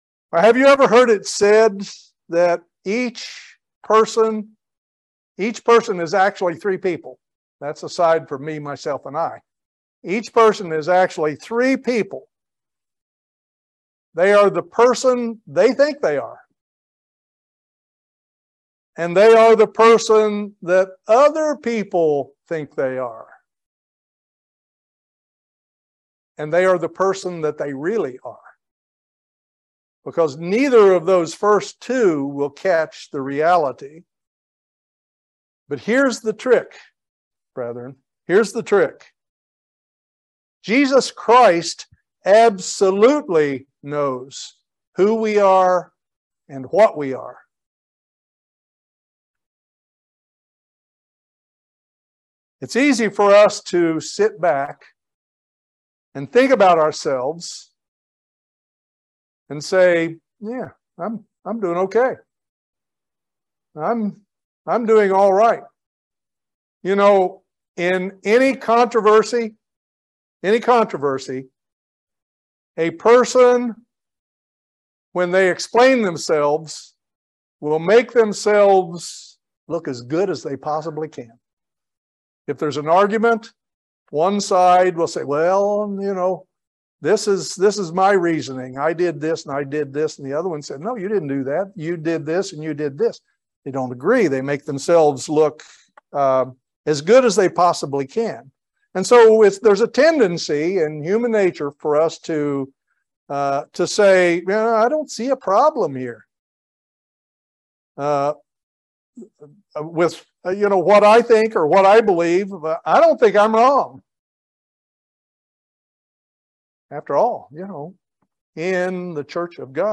This sermon discusses the prevailing attitudes of the two end time eras of God's Church. It talks about the importance of following Christ's admonition to those of Philadelphia to hold on and continue faithfully to complete the preaching of the Gospel to the very end.